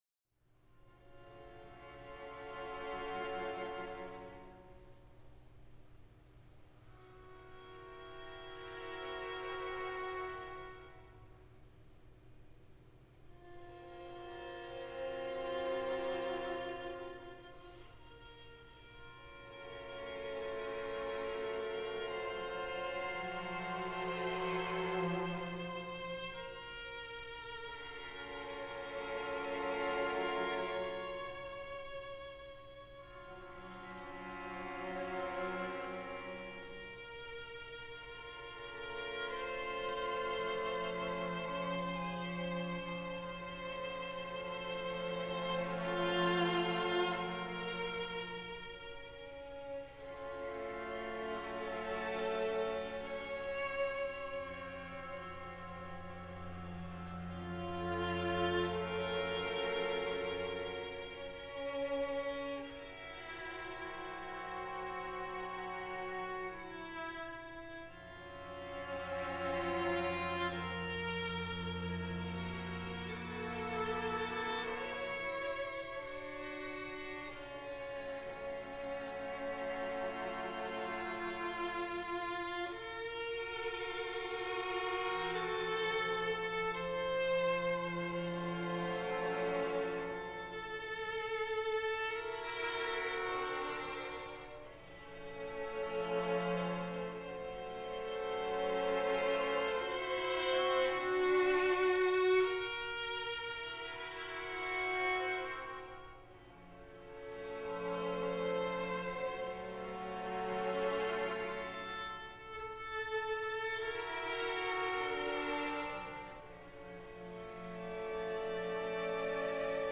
string sextet
Doors open at 5:30 p.m. for general seating in the fourth-floor gallery.
The Bechtler performance of Risen https